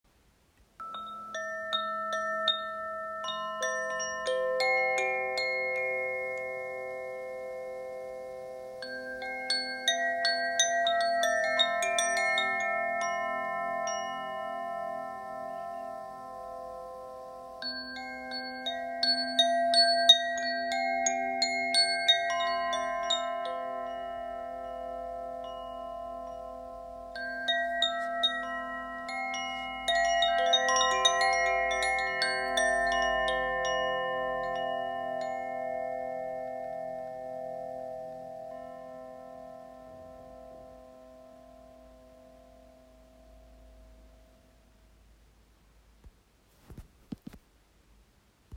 Wind chime
• 8 integrated metal rods
• Terra Tuning: G, C, E, F, G, C, E, G